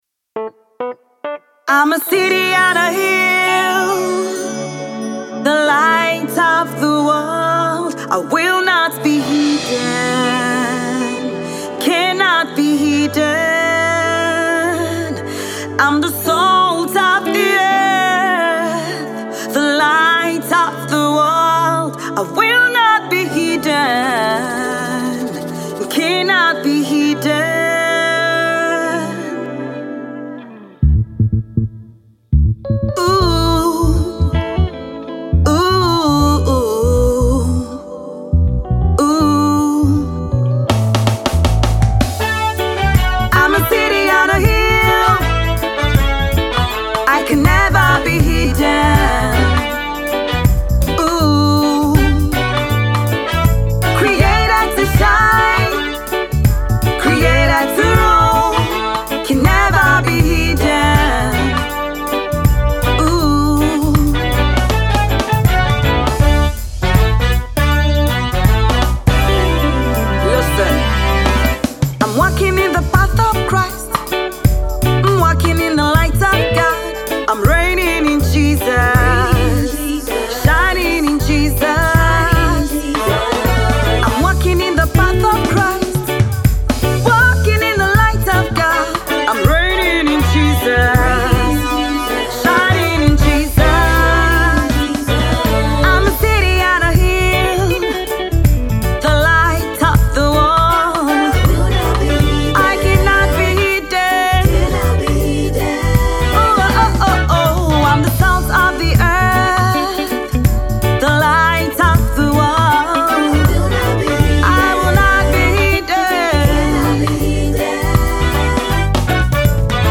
International gospel minister